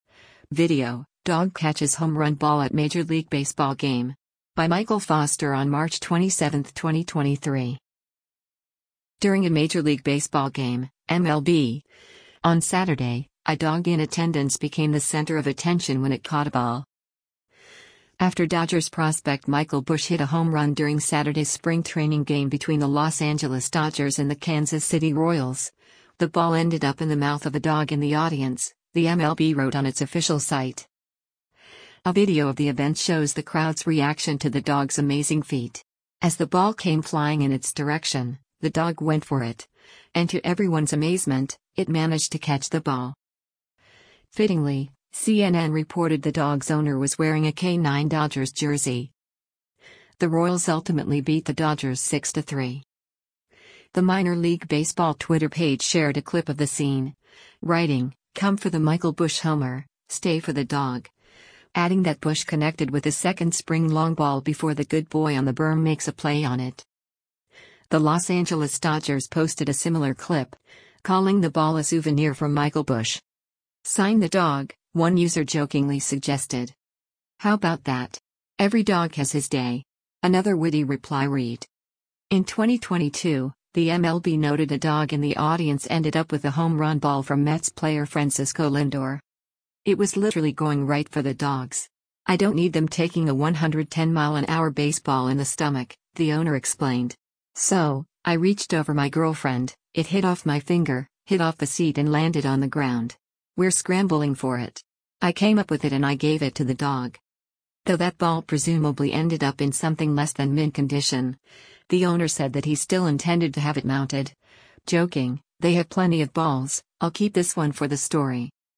A video of the event shows the crowd’s reaction to the dog’s amazing feat. As the ball came flying in its direction, the dog went for it, and to everyone’s amazement, it managed to catch the ball.